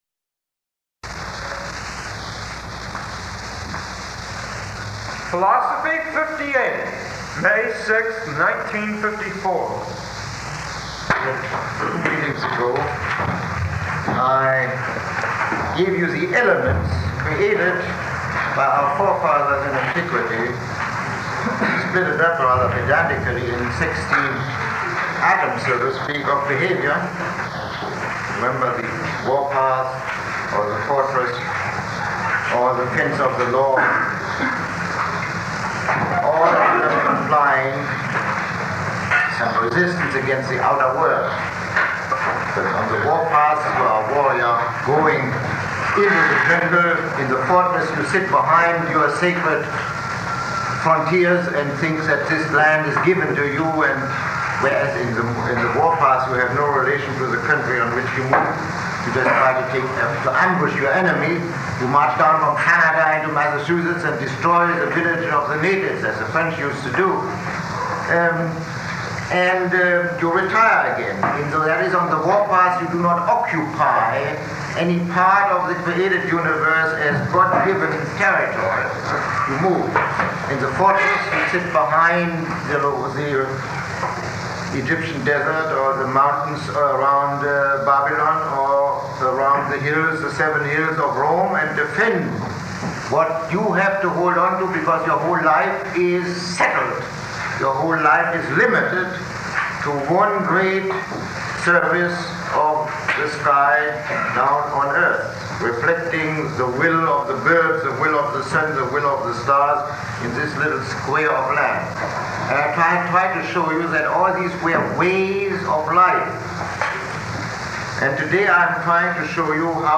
Lecture 18